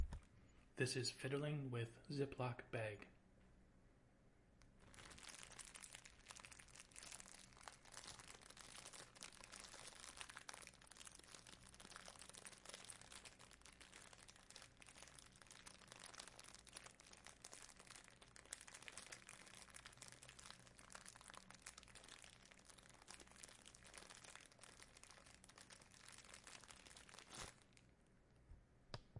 木工蜂
描述：领地型木匠蜂（也被称为大黄蜂）忙着在木材上工作。嗡嗡声从工人的啃咬声到试图恐吓入侵者进入蜜蜂的空间时使用的攻击性飞行嗡嗡声不等。城市背景声音：交通噪音和鸣鸟。
标签： 错误 场 - 记录 昆虫
声道立体声